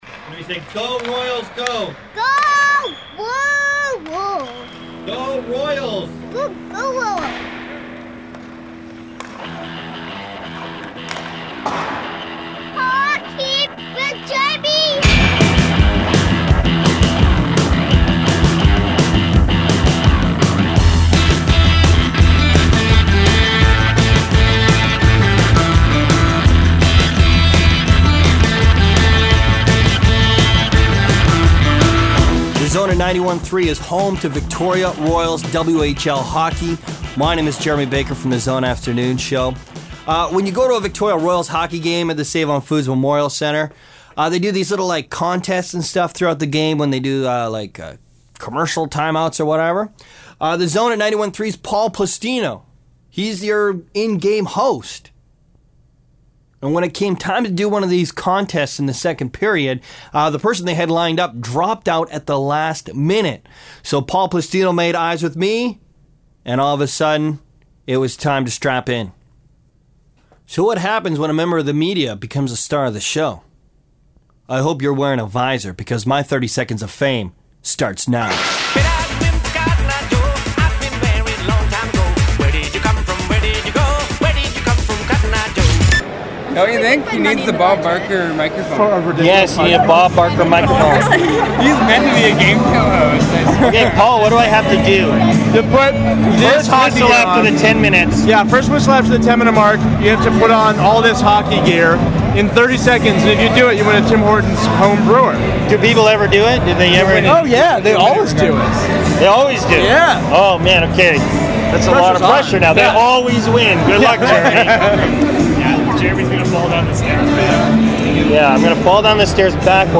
In a spectacular turn of fate, I get a chance to take part in a hockey game contest at the Save-On Foods Memorial Arena at a Victoria Royal’s WHL hockey game.